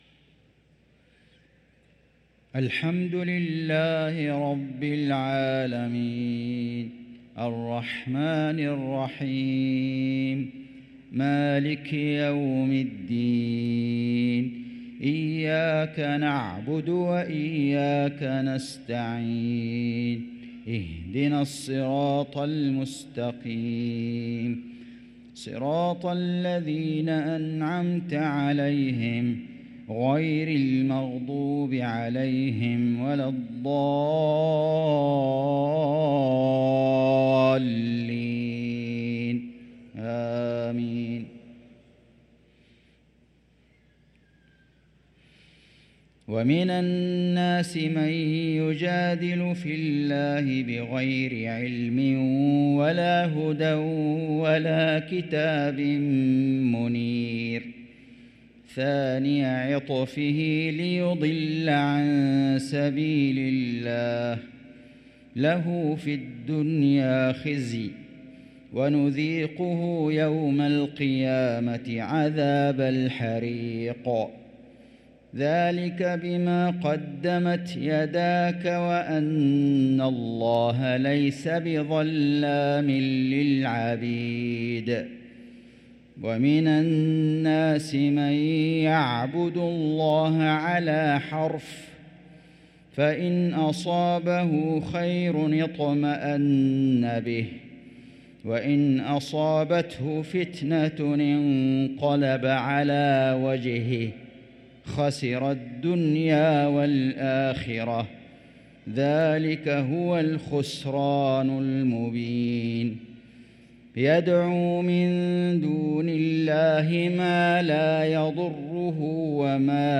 صلاة العشاء للقارئ فيصل غزاوي 3 صفر 1445 هـ
تِلَاوَات الْحَرَمَيْن .